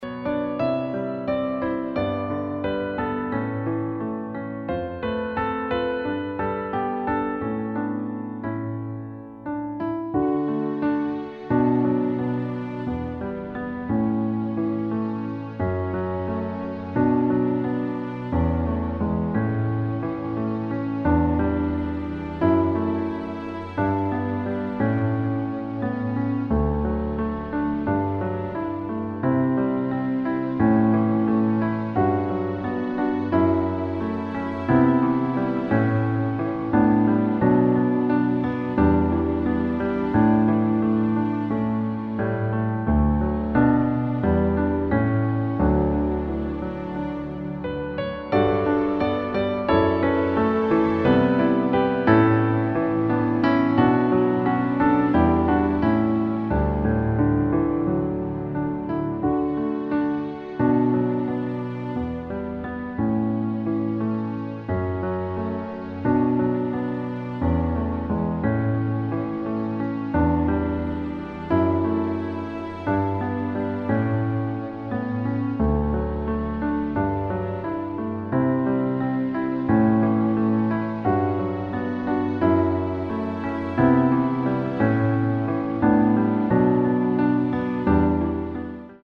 Gabenbereitungslied
• Tonart: Bb Dur, C Dur, D Dur (weitere auf Anfrage)
• Art: Klavier Streicher Version
• Das Instrumental beinhaltet NICHT die Leadstimme
Klavier / Streicher